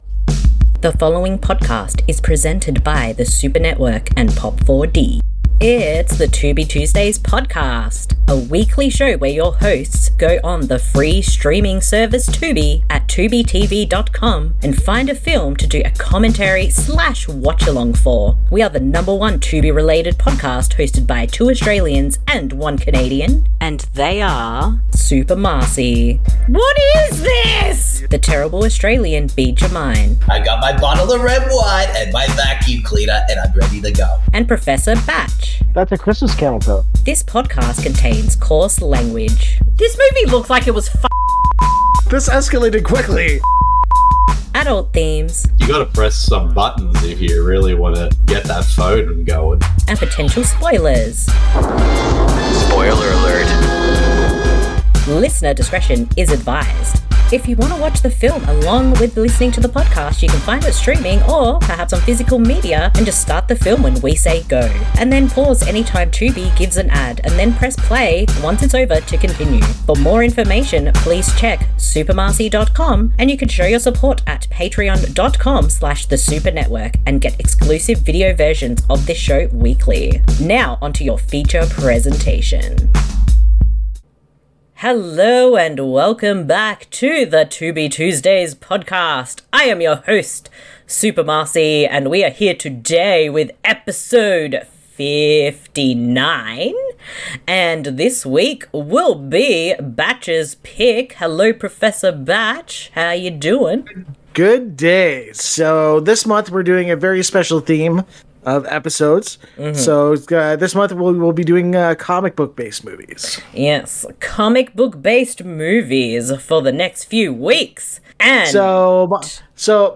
This podcast series is focused on discovering and doing commentaries/watch a longs for films found on the free streaming service Tubi, at TubiTV
Because we have watched the films on Tubi, it is a free service and there are ads, however we will give a warning when it comes up, so you can pause the film and provide time stamps to keep in sync.